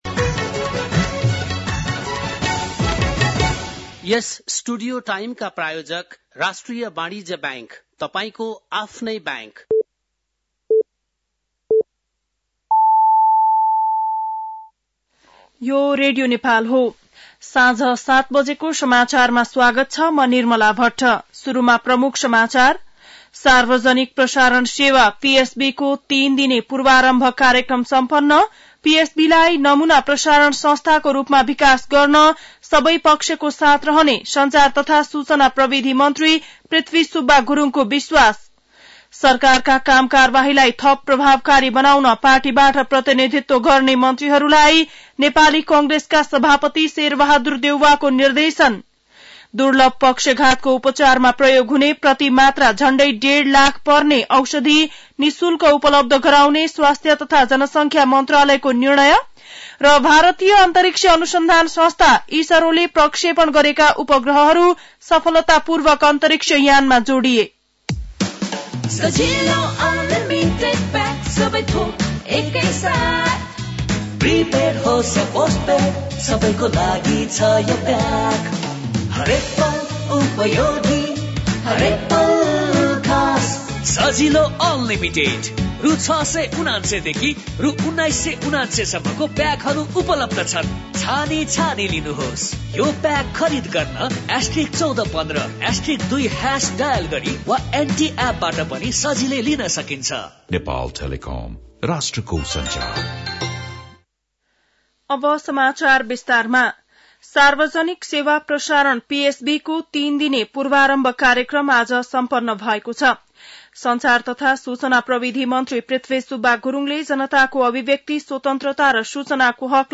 बेलुकी ७ बजेको नेपाली समाचार : ४ माघ , २०८१
7-pm-news-2.mp3